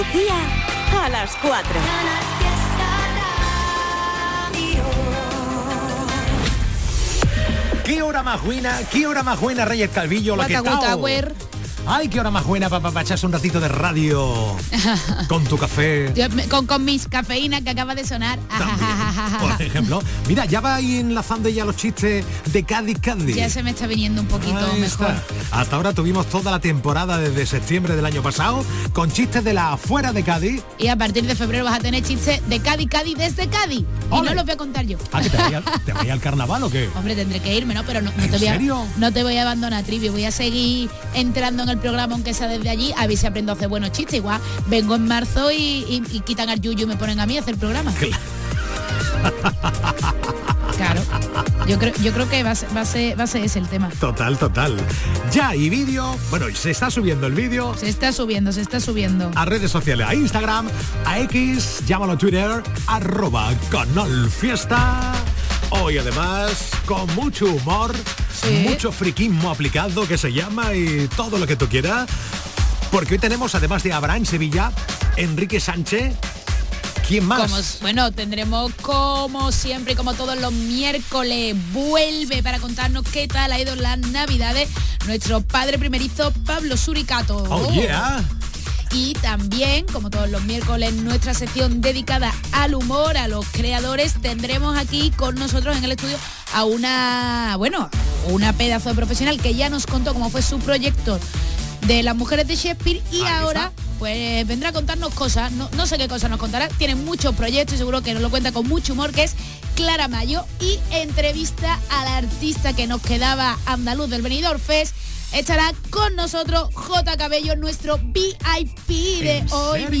Es toda una fiesta. Y tú, ¿todavía no eres company?Canal Fiesta | De lunes a viernes, de 16:00 a 19:00h